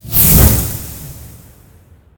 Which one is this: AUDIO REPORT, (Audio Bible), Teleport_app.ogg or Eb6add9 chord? Teleport_app.ogg